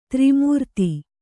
♪ tri mūrti